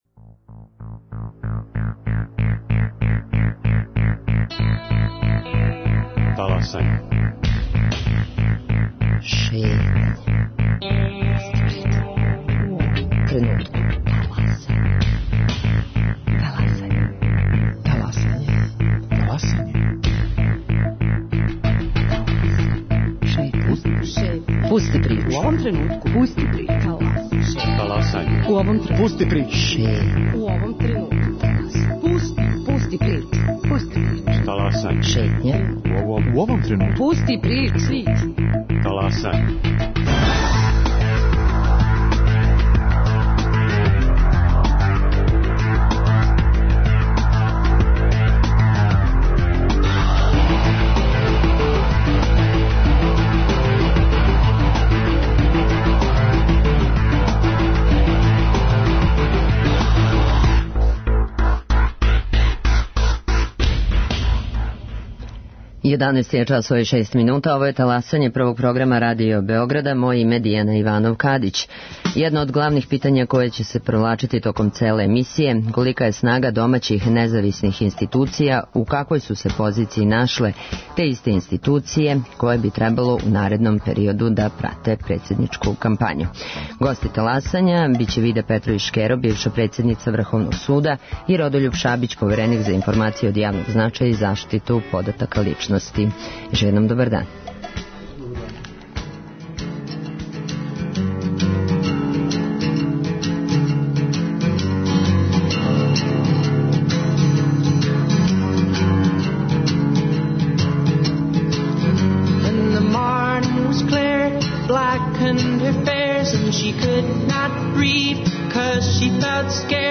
Гости Таласања Вида Петровић Шкеро, бивша председница Врховног суда и Родољуб Шабић, повереник за информације од јавног значаја и заштиту података личности.